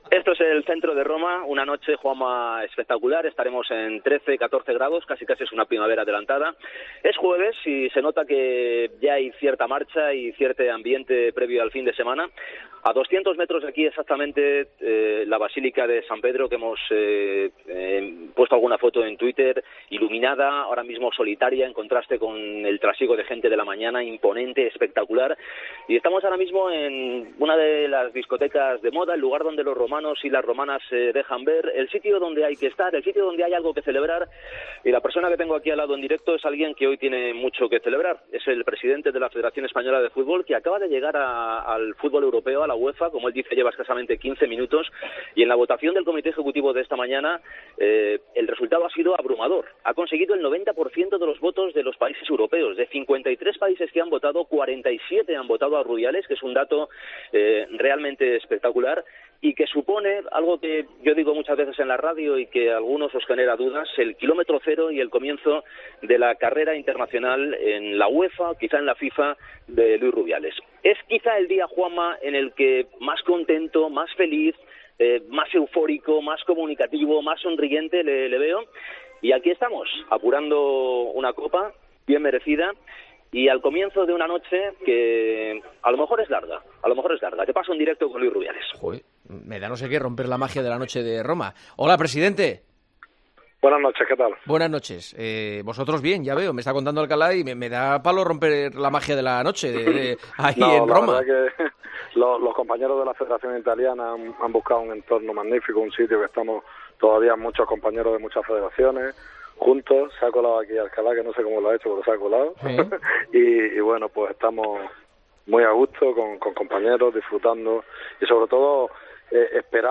AUDIO: El presidente de la Federación Española aseguró en El Partidazo de COPE que en abril se conocerá el nuevo formato para la Copa del Rey.